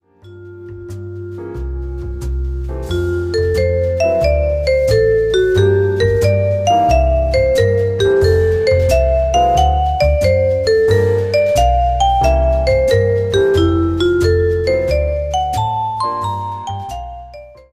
Achtet darauf schön swingig zu frasieren! (
also eher so und